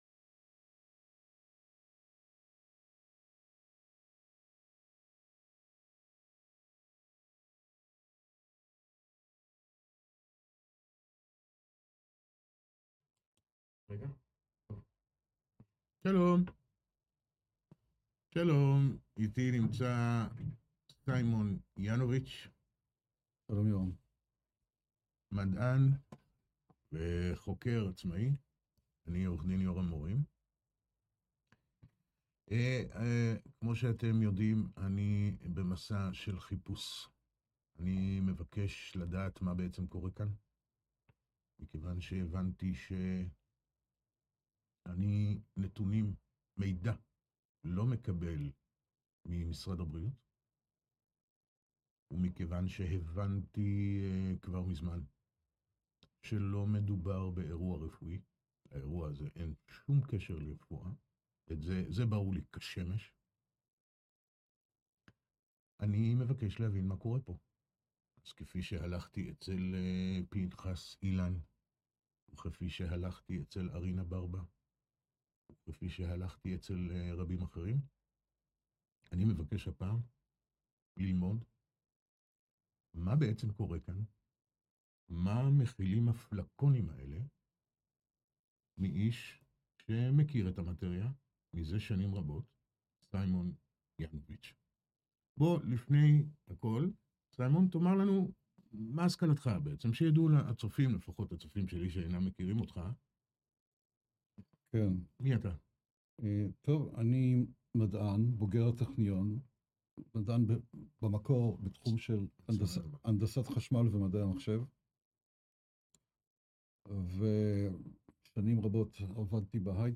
בתחילת הסרטון שומעים חלש אחר כך זה מסתדר